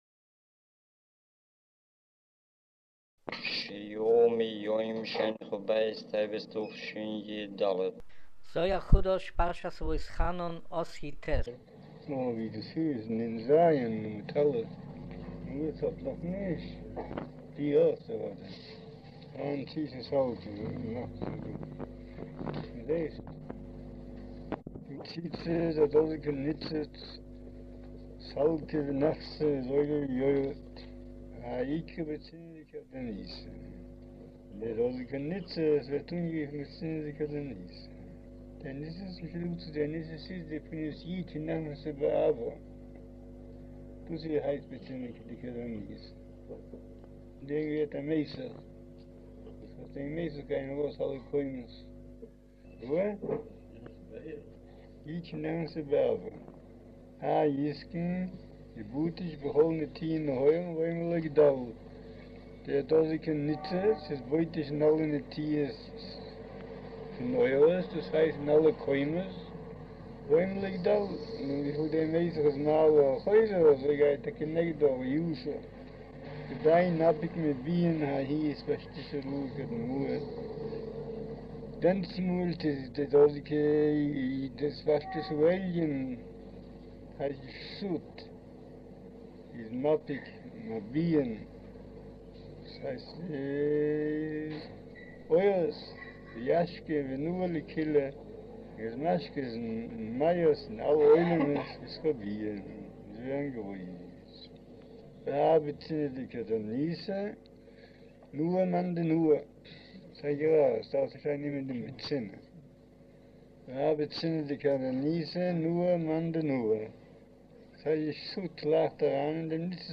אודיו - שיעור